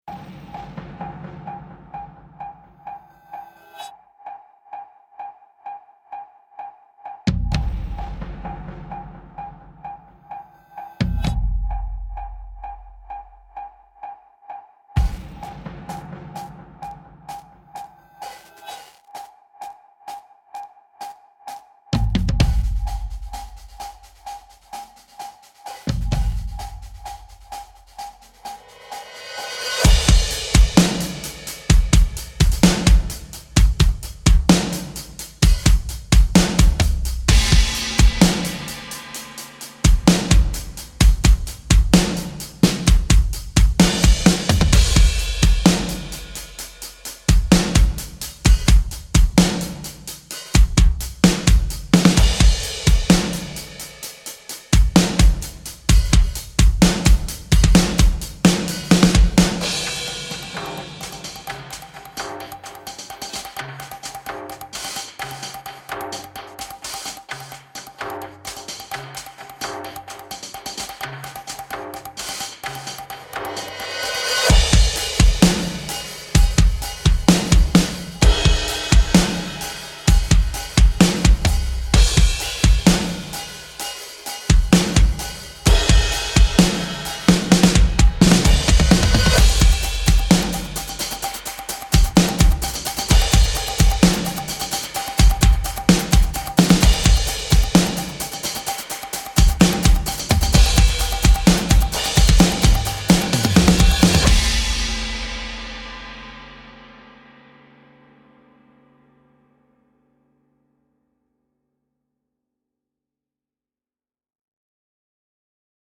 具有优秀打击感的强大打击乐库
Damage Kit 具备最沉重的鼓声素材，能够为你的配乐画龙点睛
高强度打击感
• 完整的鼓组（干净和大肆宣传的版本） - 2个踢鼓，3个圈套，4个汤姆，Hi-Hat，7个钹，棍棒咔哒声，牛铃